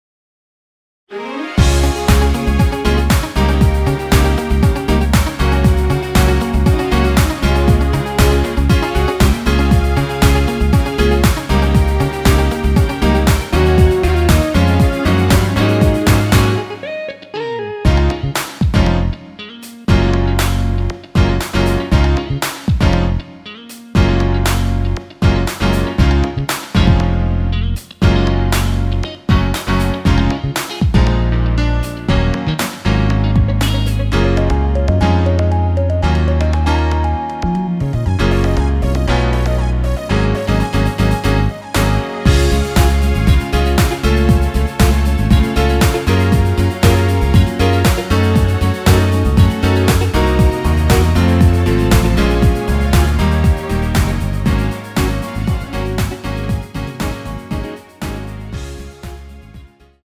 Db
앞부분30초, 뒷부분30초씩 편집해서 올려 드리고 있습니다.
중간에 음이 끈어지고 다시 나오는 이유는
곡명 옆 (-1)은 반음 내림, (+1)은 반음 올림 입니다.